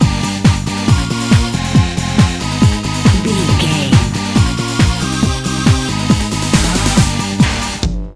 Uplifting
Aeolian/Minor
Fast
drum machine
synthesiser
electric piano
conga